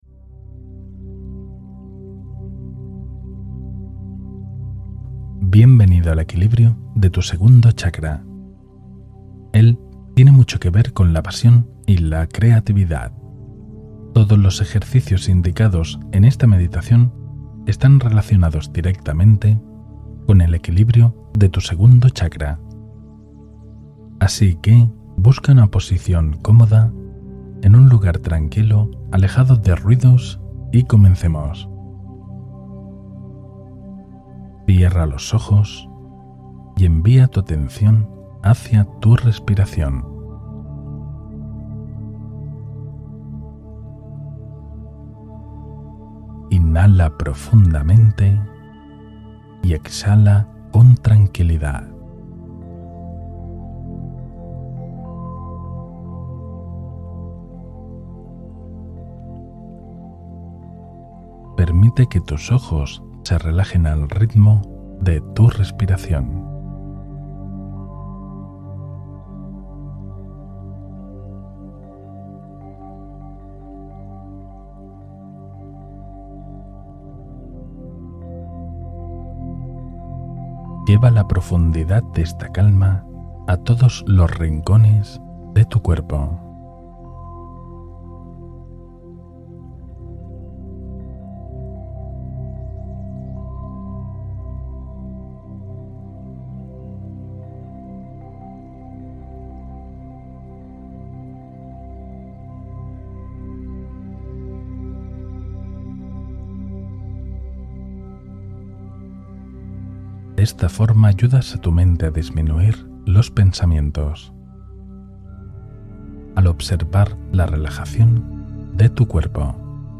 Meditación Profunda para Encender el Segundo Chakra